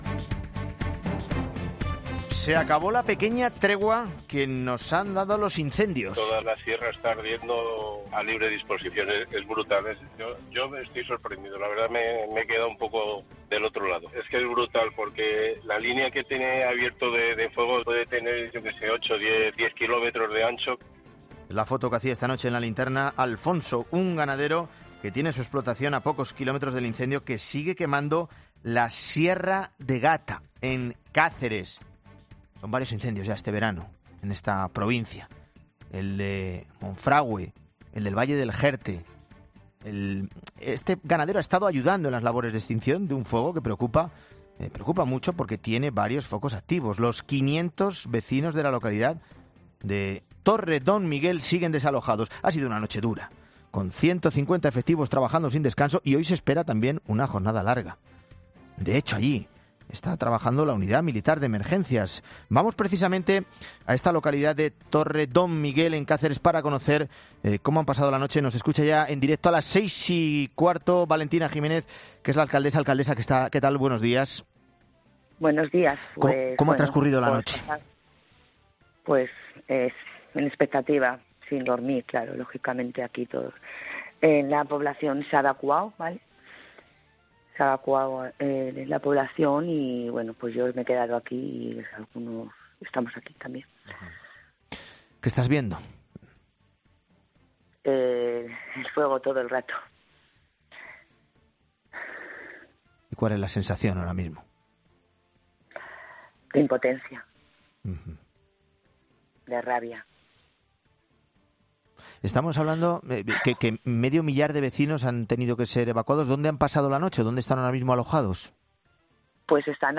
La alcaldesa de Torre de Don Miguel atiende la llamada de 'Herrera en COPE' para valorar el incendio